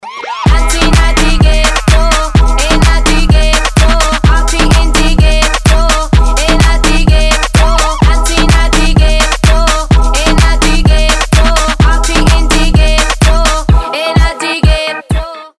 ритмичные
заводные
house